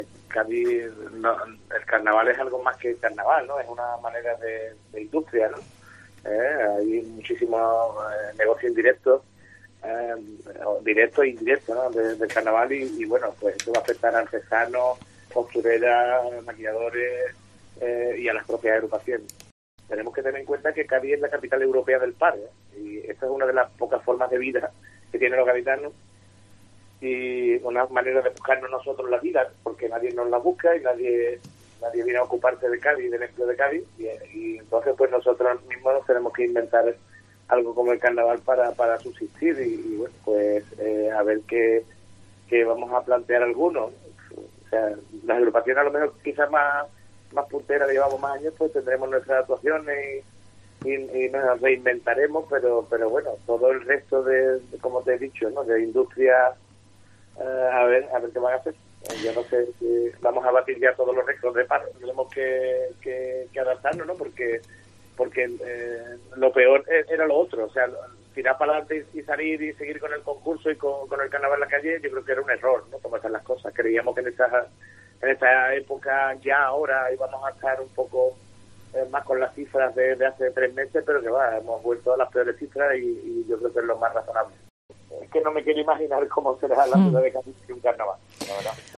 En COPE ANDALUCÍA entrevistamos a uno de los grandes chirigoteros del Carnaval gaditano, que cree que tampoco habrá Carnaval en 2021